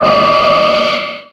Audio / SE / Cries / GARDEVOIR.ogg